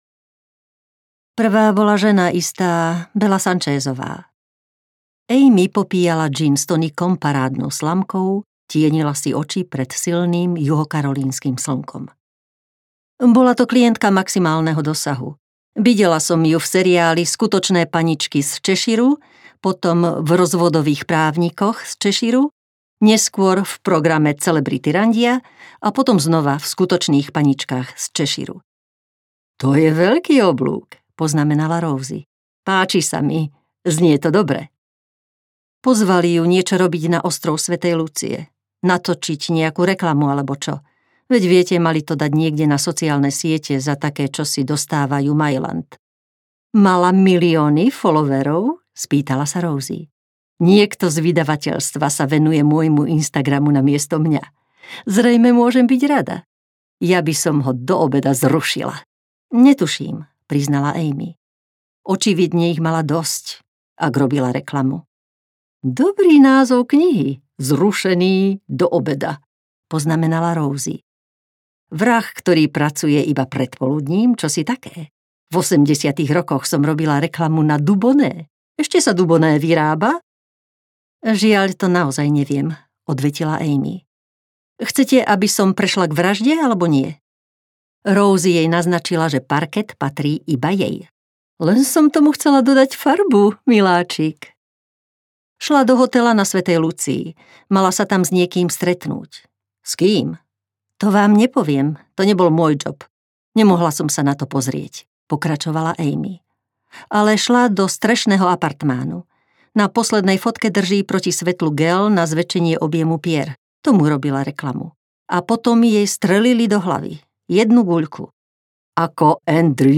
Riešime vraždy audiokniha
Ukázka z knihy
riesime-vrazdy-audiokniha